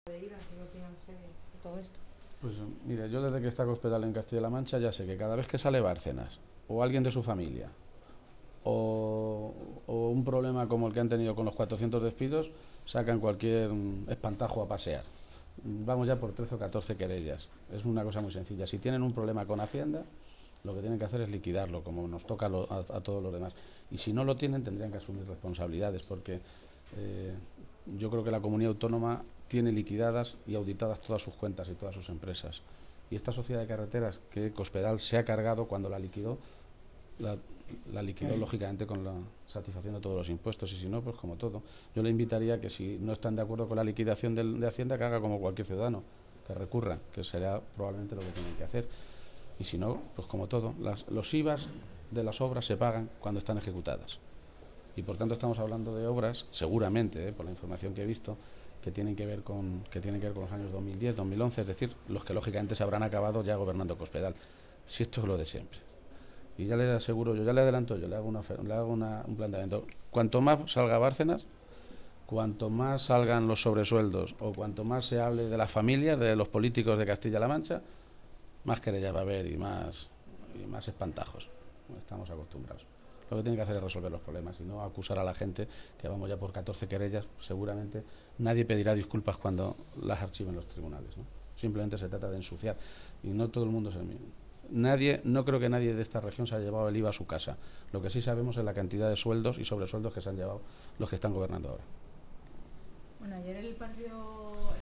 García-Page se pronunciaba de esta manera esta mañana, en Toledo, a preguntas de los medios de comunicación, que le preguntaban por la rueda de prensa ofrecida ayer por la consejera de Fomento en la que, según ella, la Sociedad de Carreteras de Castilla-La Mancha no había pagado el IVA de algunas obras relativas a los años 2010 y 2011.
Cortes de audio de la rueda de prensa